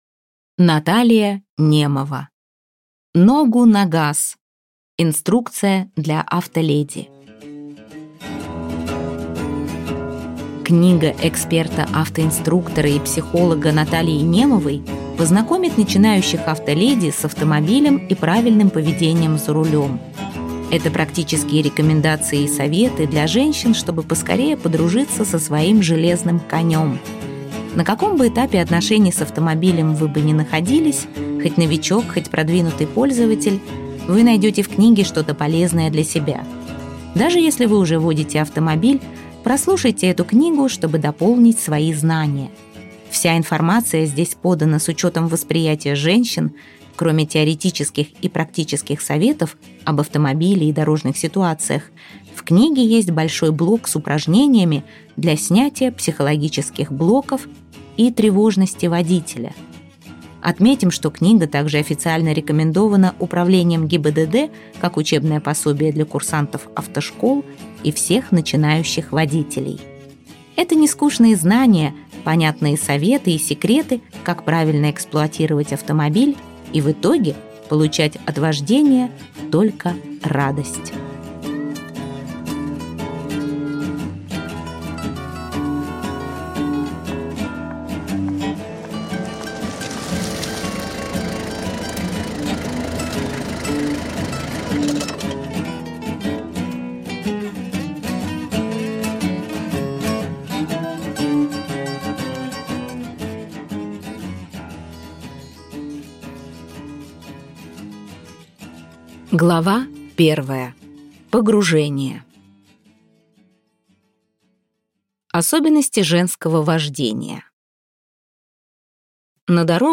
Аудиокнига Ногу на газ! Инструкция для автоледи | Библиотека аудиокниг